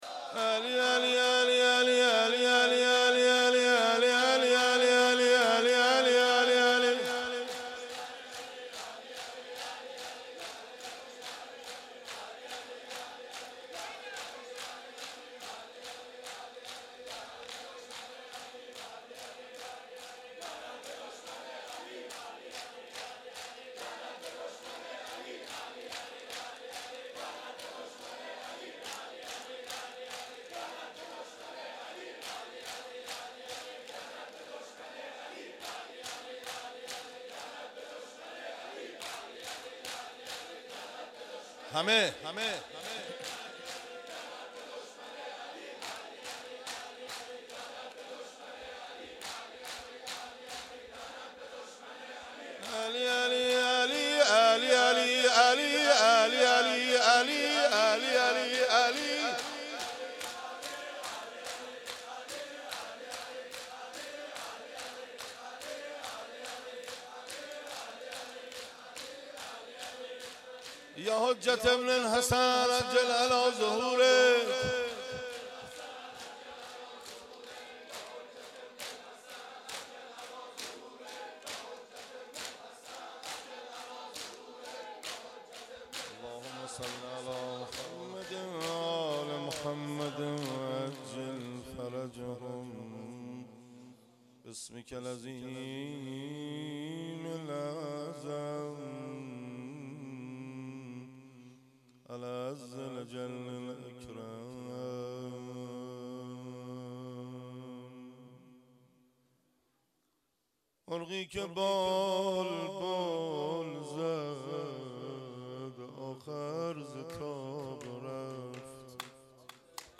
مناسبت : دهه دوم محرم
قالب : شور